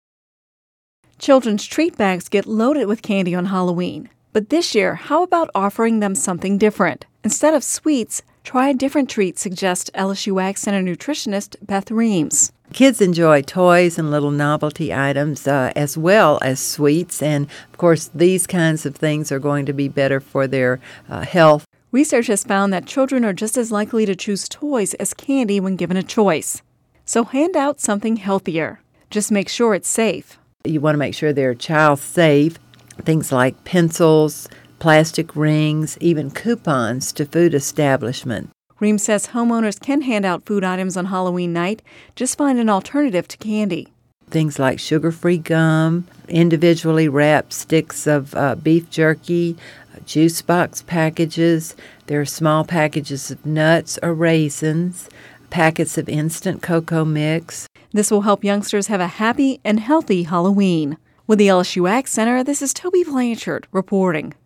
(Radio News 10/18/10) Children’s treat bags get loaded with candy on Halloween, but how about offering them something different this year?